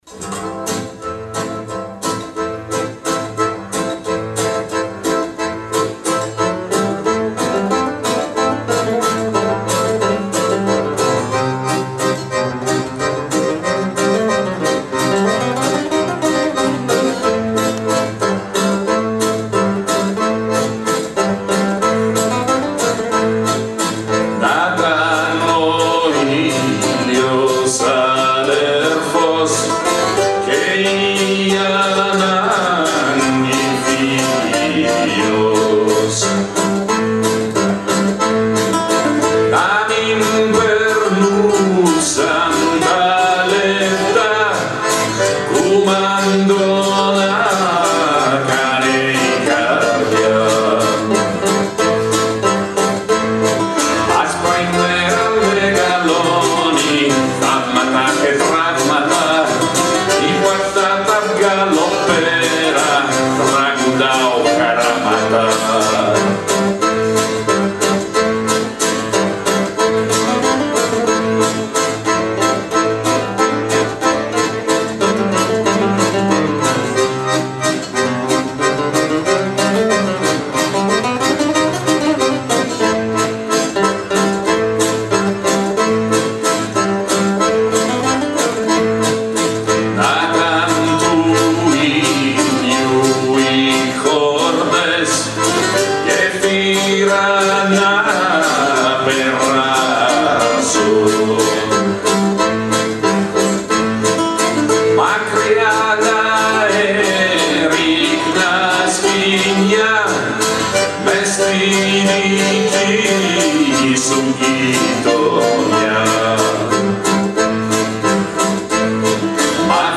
voce e chitarra
bayan) - Dal vivo, Nonantola